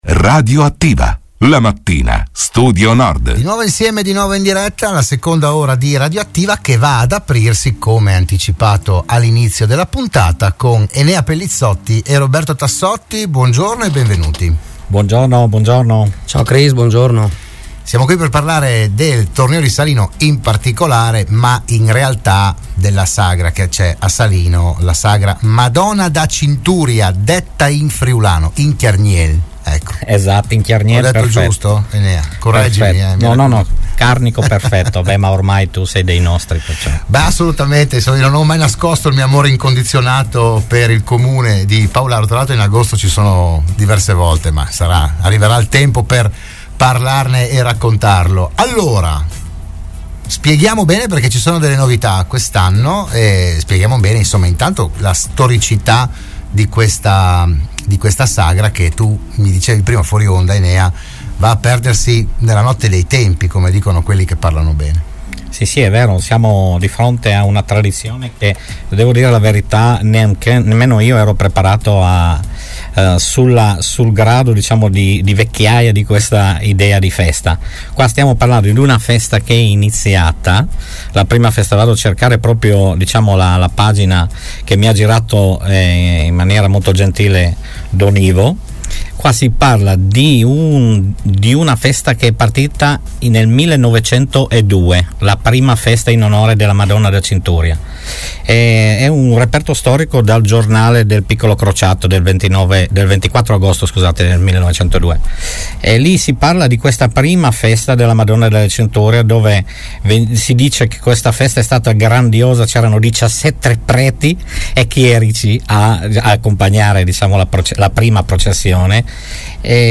Dell’iniziativa si è parlato a “RadioAttiva“, la trasmissione di Radio Studio Nord